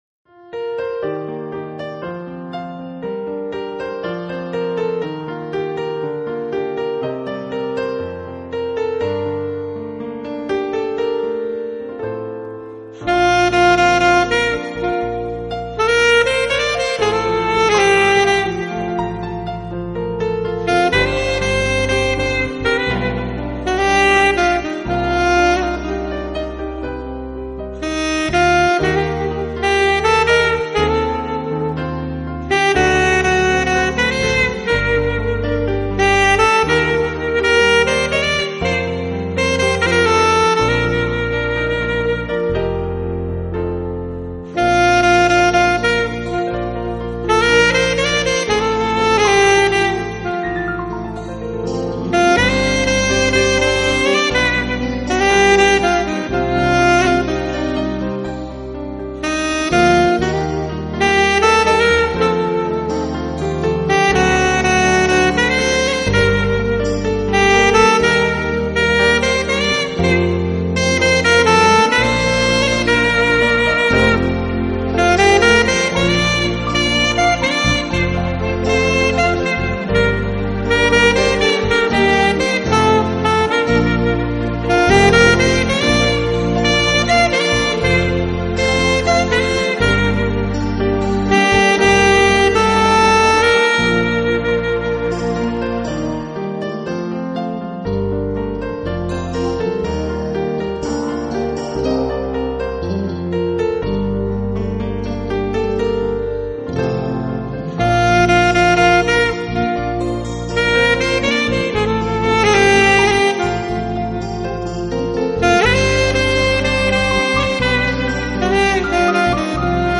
纯音萨克斯